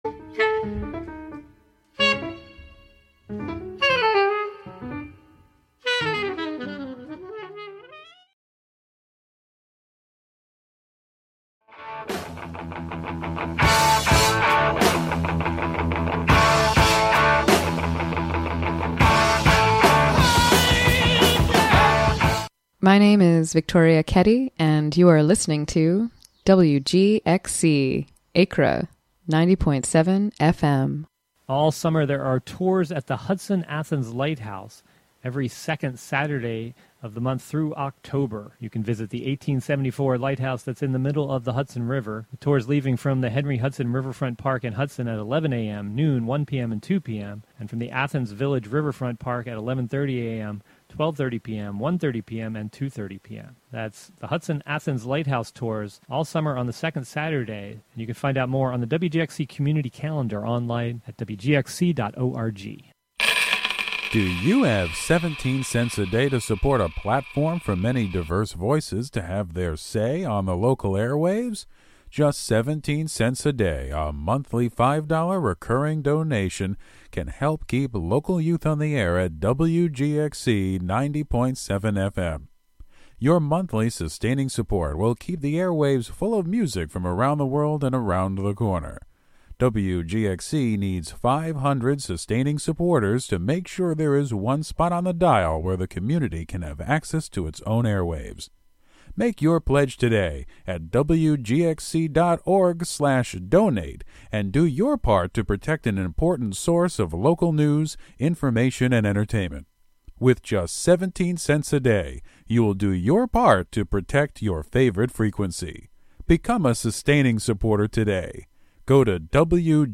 Broadcast from Catskill.